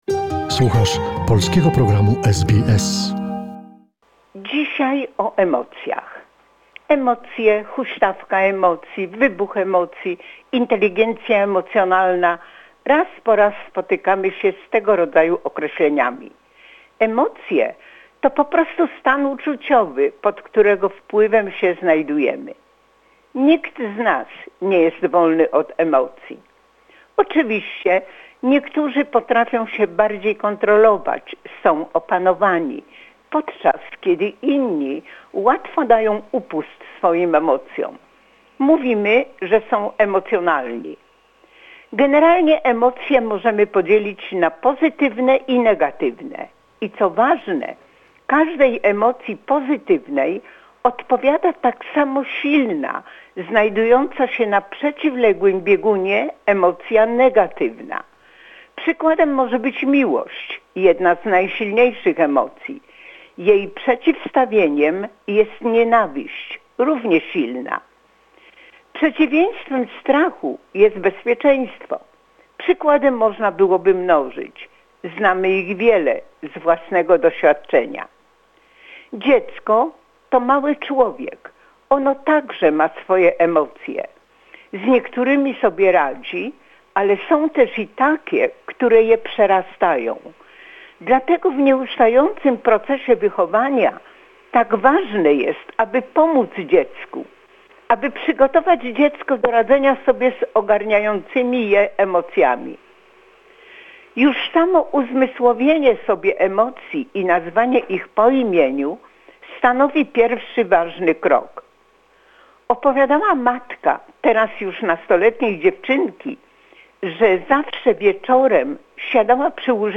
Mówi pedagog